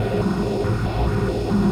Index of /musicradar/rhythmic-inspiration-samples/140bpm